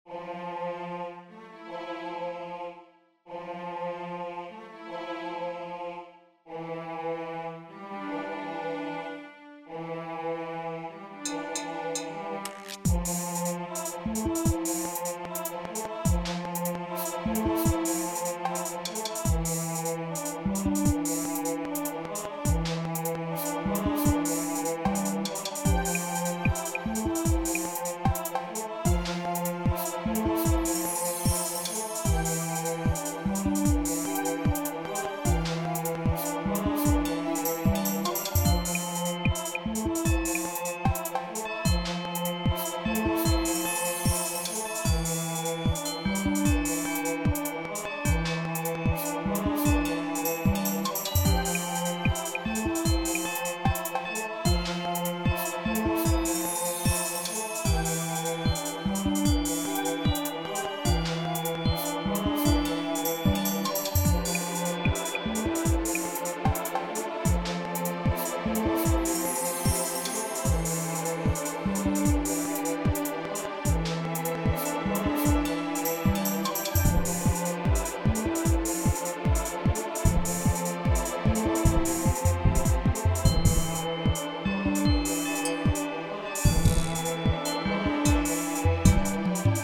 Category Music / Game Music
dream trance hip_hop